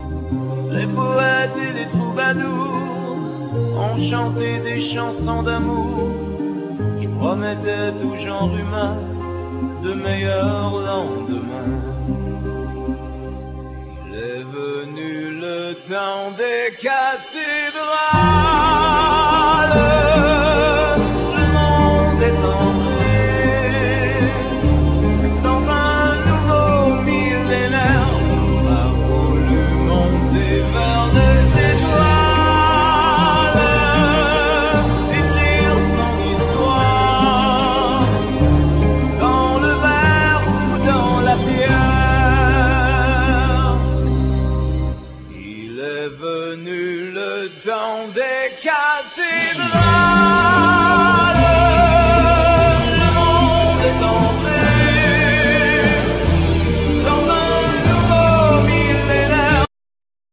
Keyboards
Bass
Guitar
Drums
Percussions